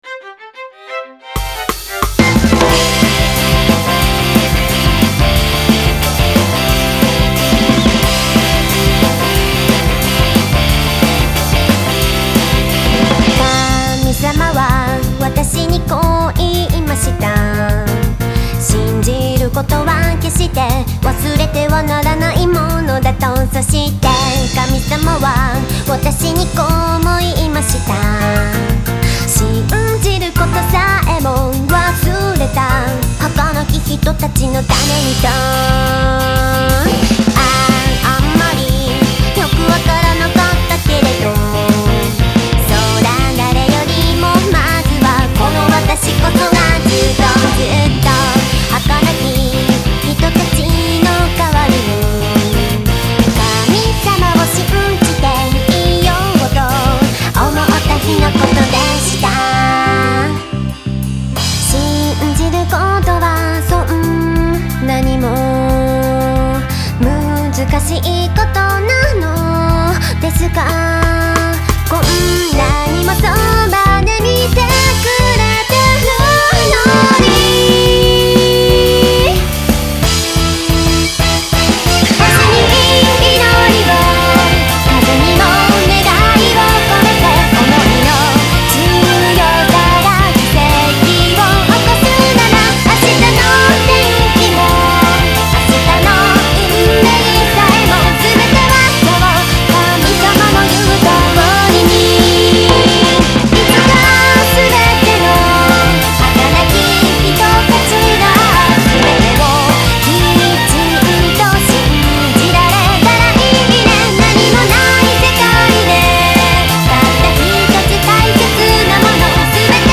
今回もボーカル、インスト、共々に様々なジャンルを詰め込んだよりどりみどりの一枚。